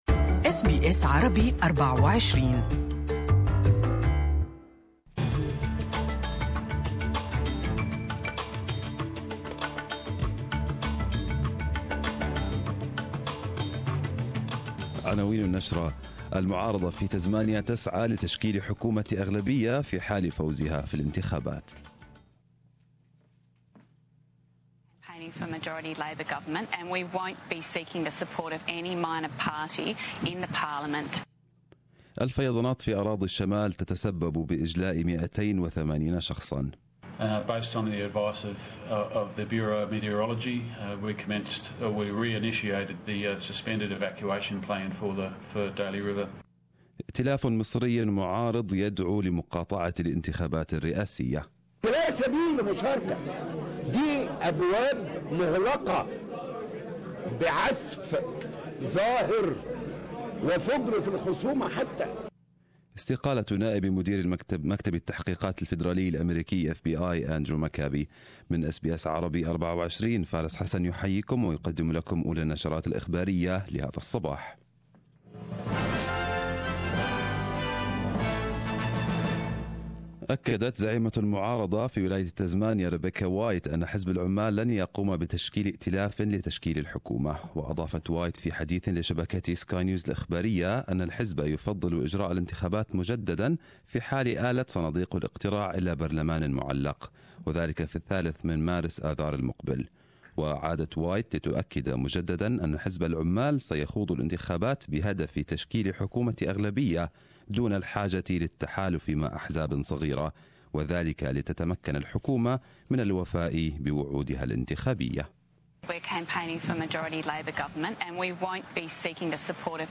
Arabic News Bulletin 31/01/2018